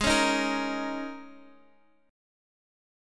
Listen to G#sus2sus4 strummed